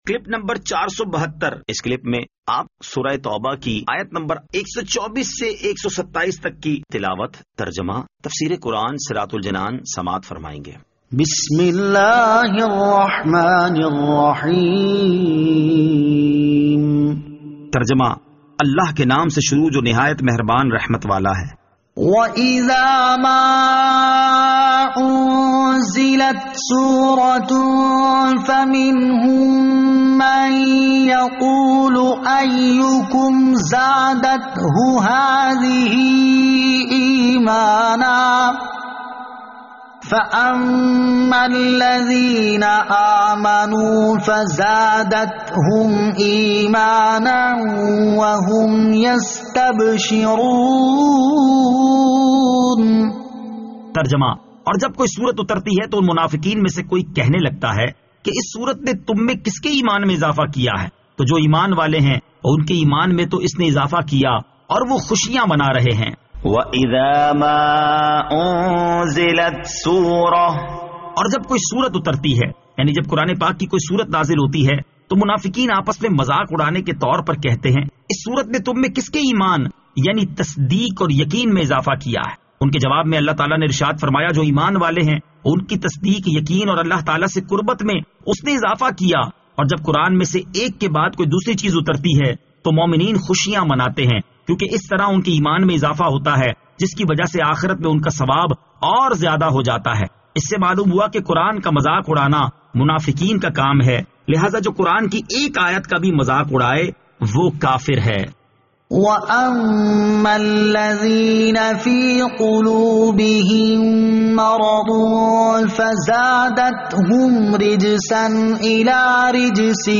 Surah At-Tawbah Ayat 124 To 127 Tilawat , Tarjama , Tafseer